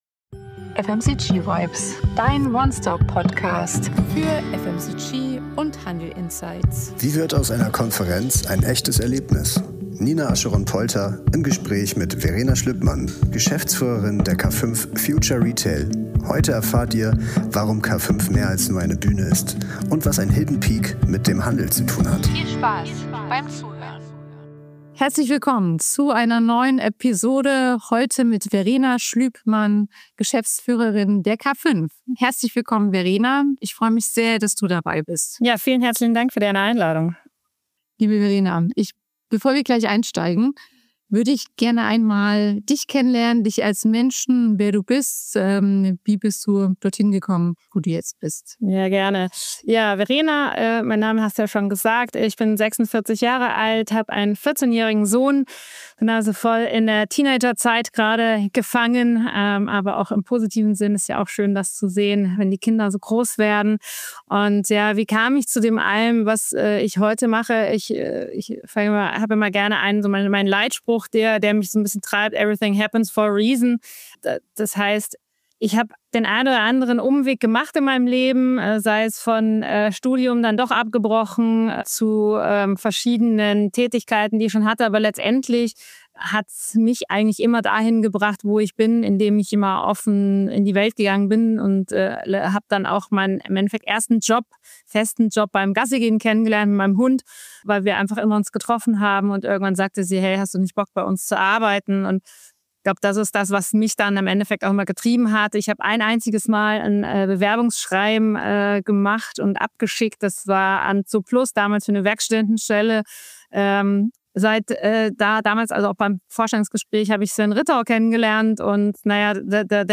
Expertinnentalk